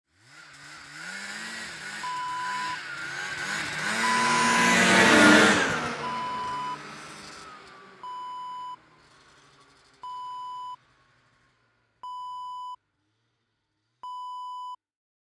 Snowmobile: Pass By Wav Sound Effect #2
Description: The sound of a snowmobile passing by
Properties: 48.000 kHz 24-bit Stereo
A beep sound is embedded in the audio preview file but it is not present in the high resolution downloadable wav file.
snowmobile-pass-by-preview-2.mp3